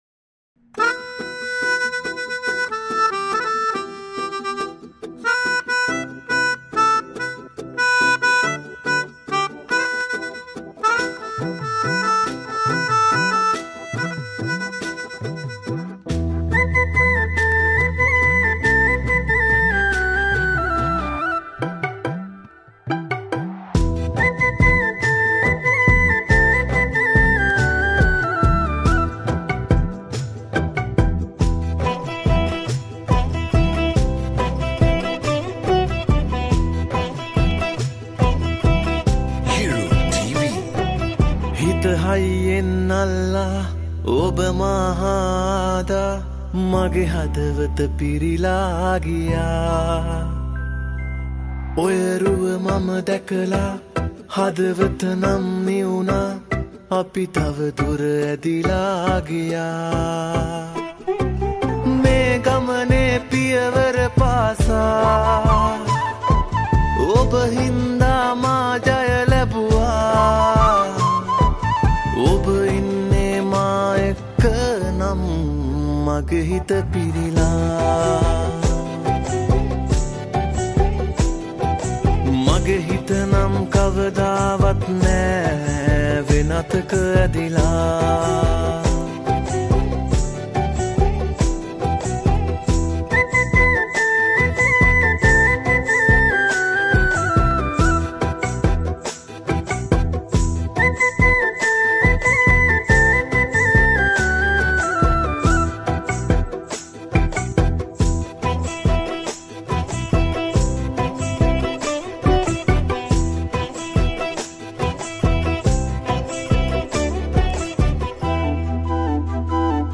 Category: Teledrama Song